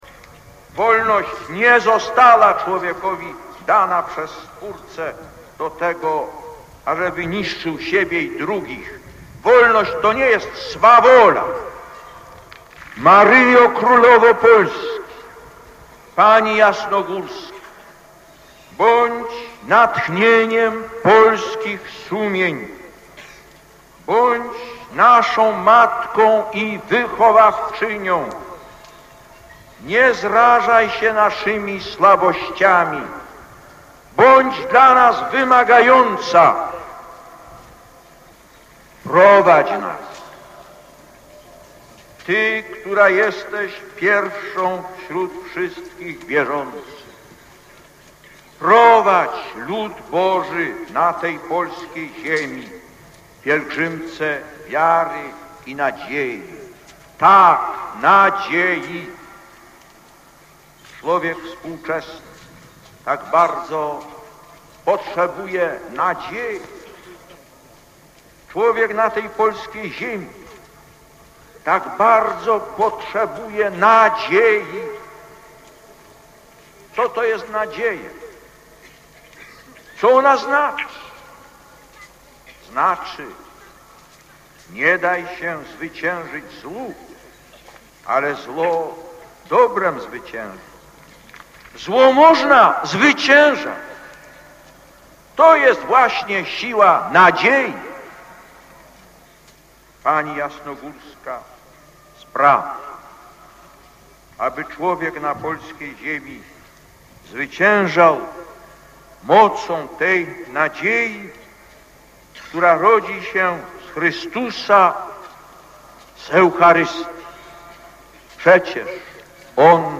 Lektor: Słowa Apelu Jasnogórskiego (Częstochowa, 12.06.1987 –